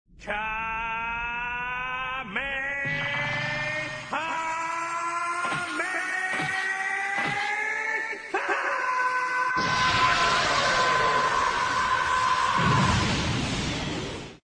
PLAY dragon ball kamehameha sound